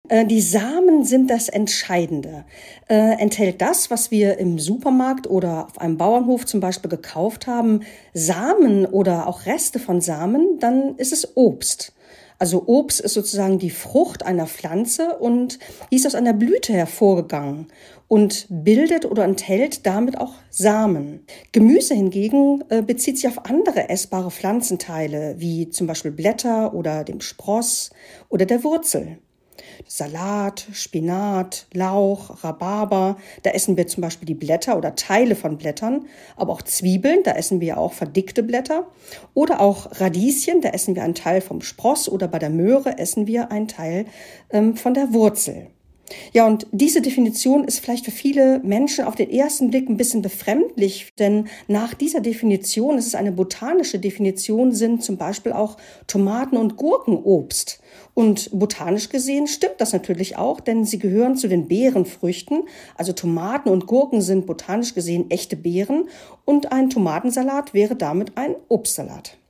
Hier erklärt eine Expertin die Merkmale.